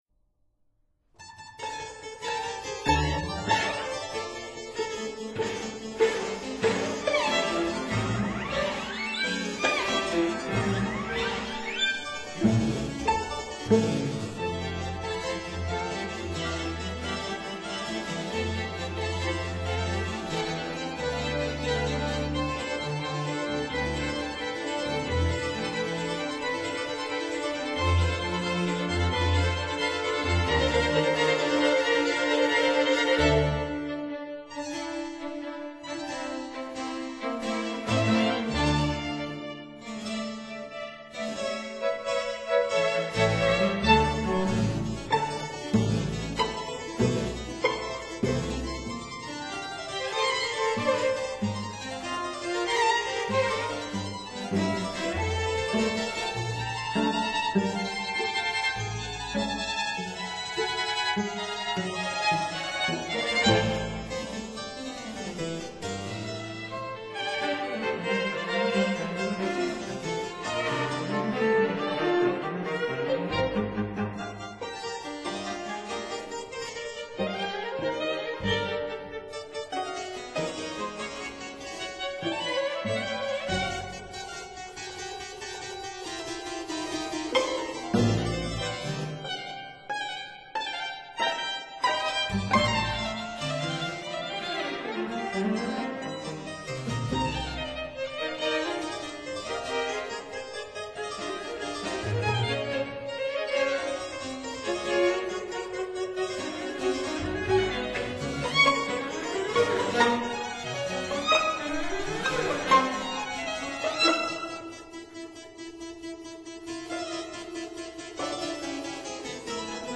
harpsichord
cello
recorder
String ensemble